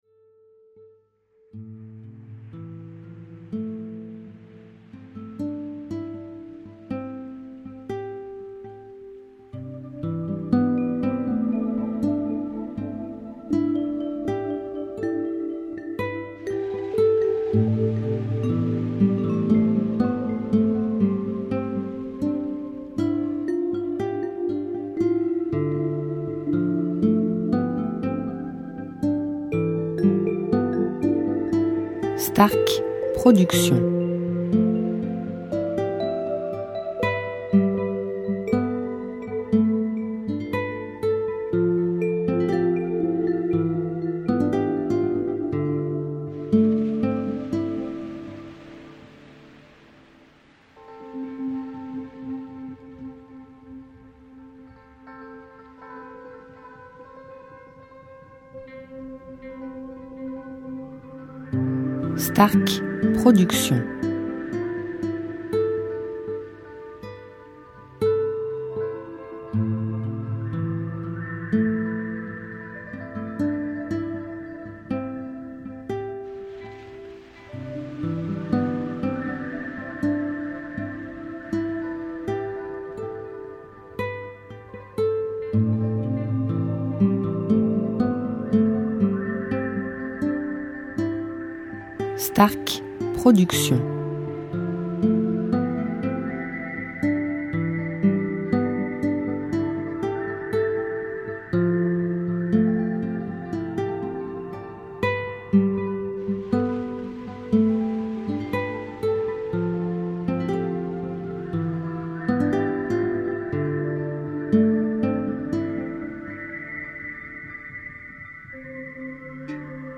style Sophrologie Méditation durée 1 heure